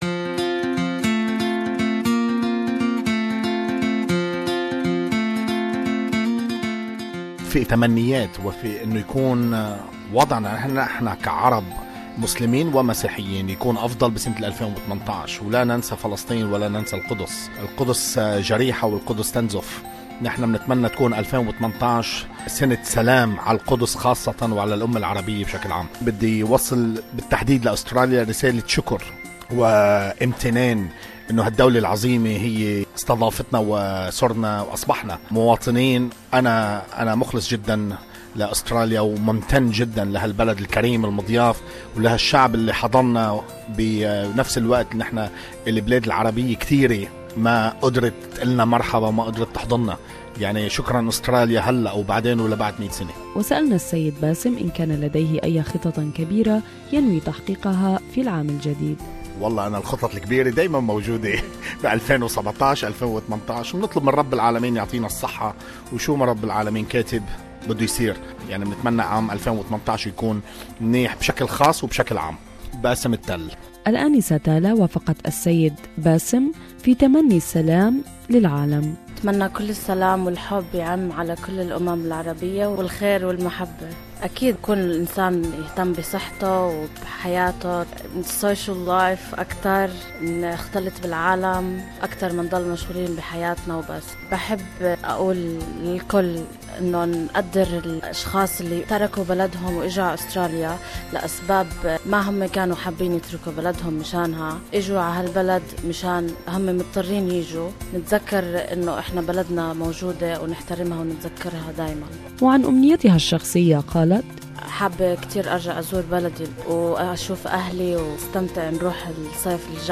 Members of the the Arabic community in Melbourne share their wishes and greetings for 2018.